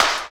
108 CLP SN-L.wav